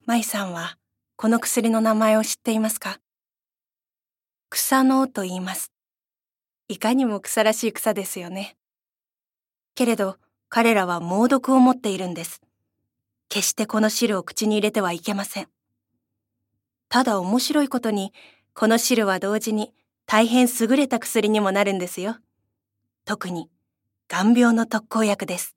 ボイスサンプル
セリフ@